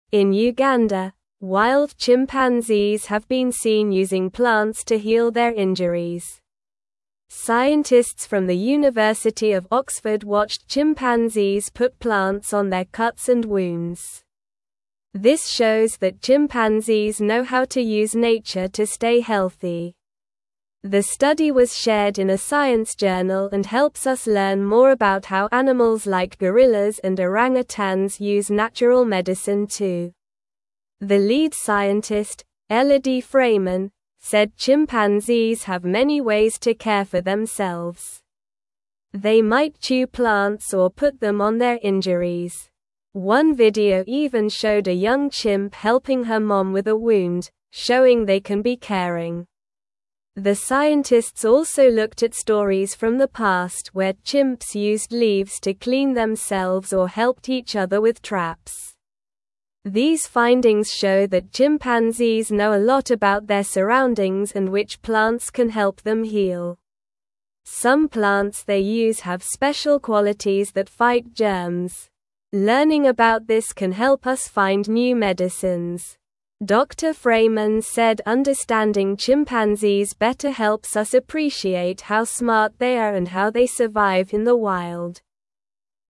Slow
English-Newsroom-Lower-Intermediate-SLOW-Reading-Chimps-Use-Plants-to-Heal-Their-Boo-Boos.mp3